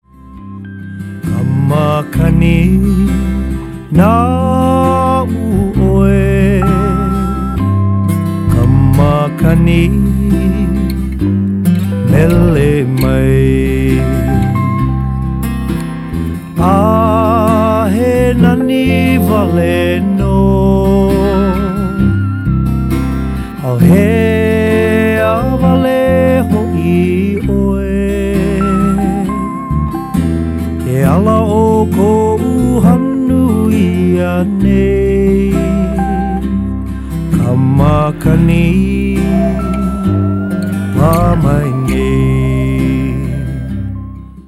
singer-'ukulele stylist
and could become a popular lullaby.
lap steel guitar adds a measure of old Hawaiian reflection